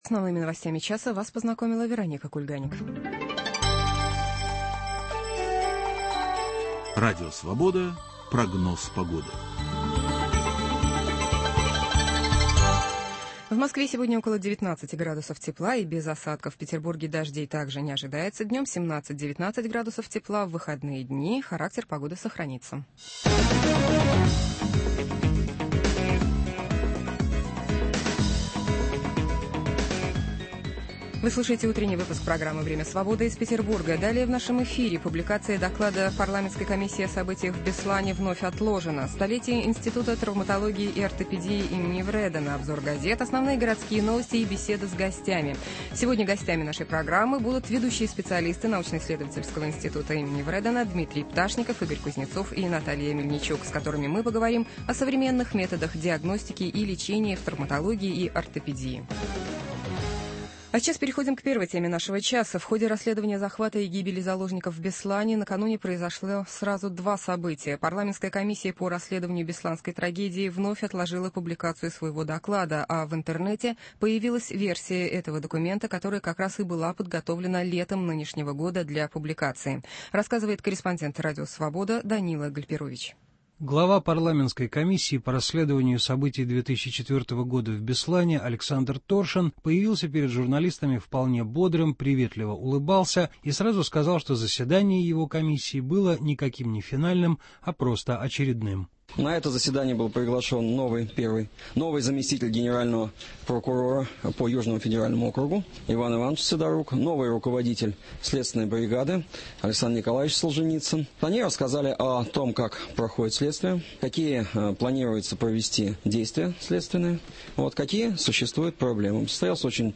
Гости в студии